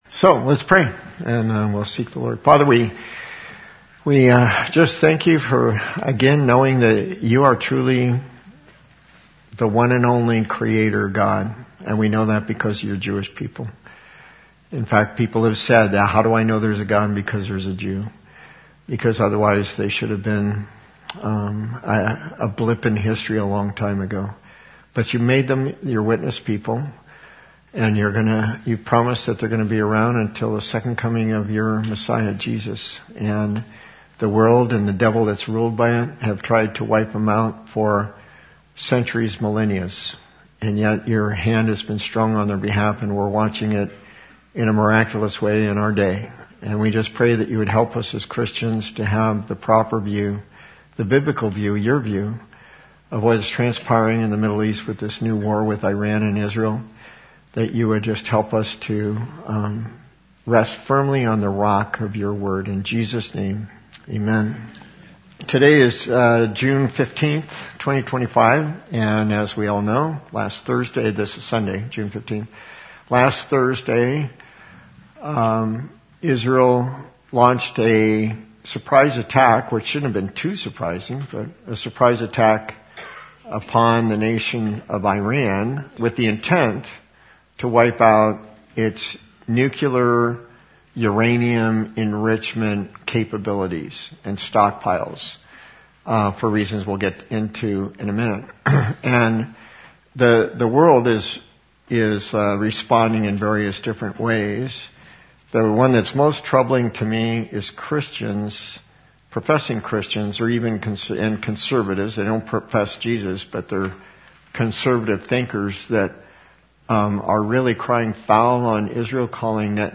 Question and Answer